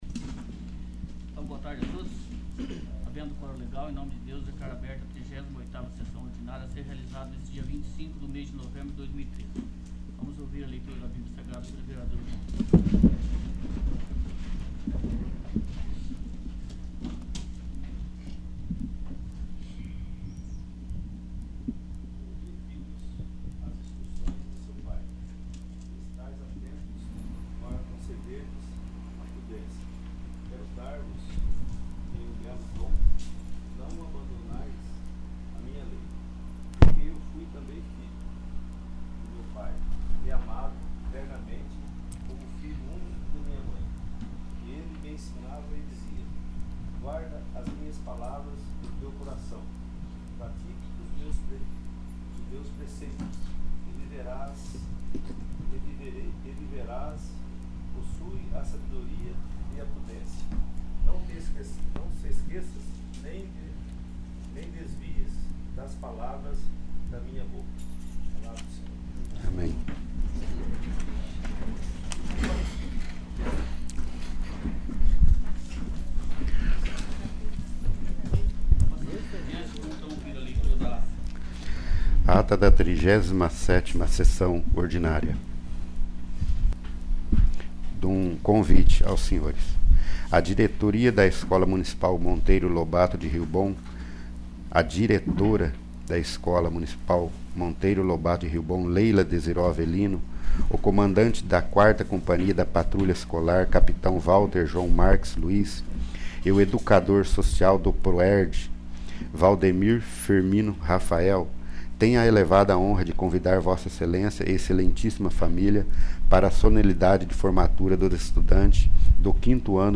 38º. Sessão Ordinária
| Ir para a navegação Ferramentas Pessoais Poder Legislativo Câmara de Vereadores do Município de Rio Bom - PR Mapa do Site Acessibilidade Contato VLibras Contraste Acessar Busca Busca Avançada…